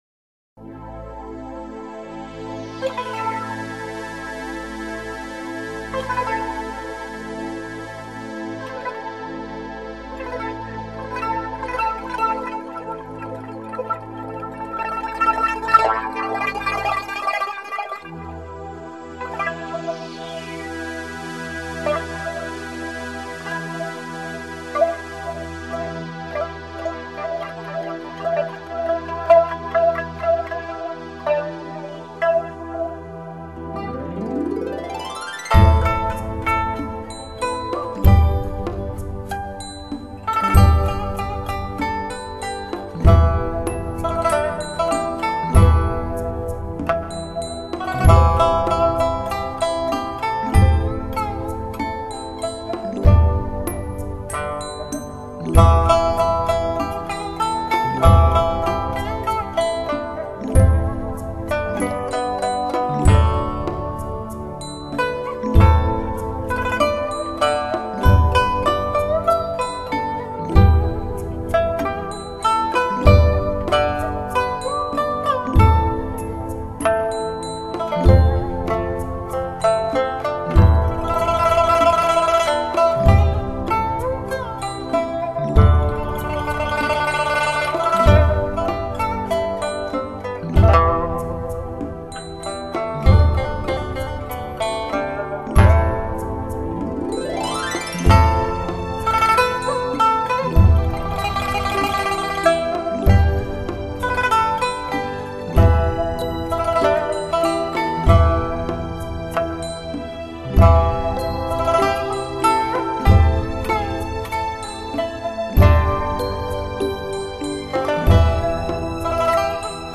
随着音乐的动律踏入那被遗忘已久永恒的宁静之旅